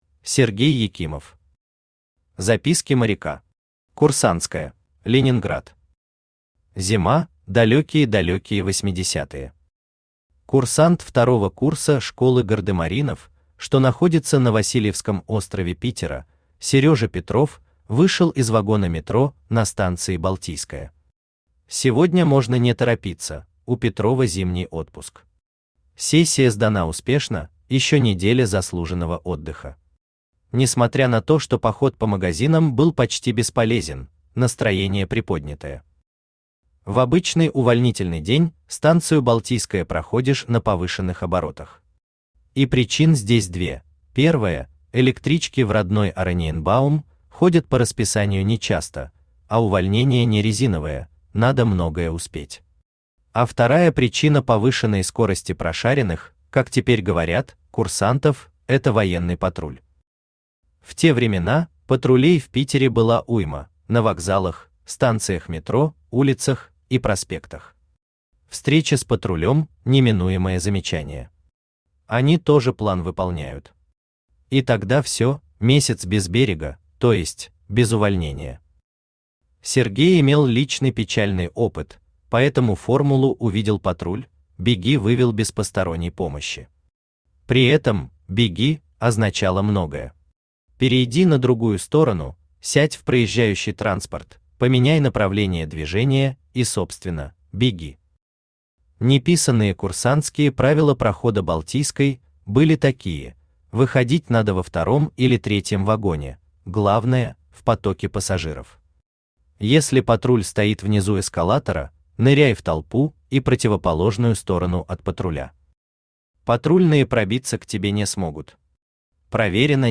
Aудиокнига Записки моряка. Курсантская Автор Сергей Петрович Екимов Читает аудиокнигу Авточтец ЛитРес.